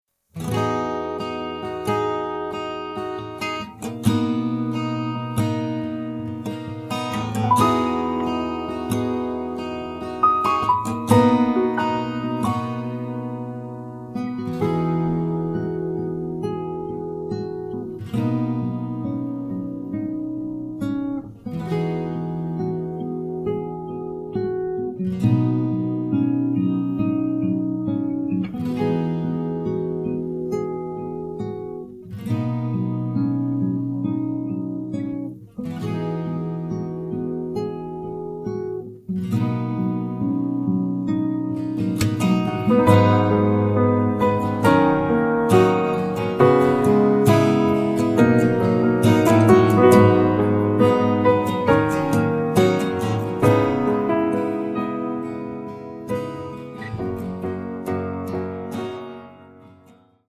음정 원키 3:43
장르 가요 구분 Voice Cut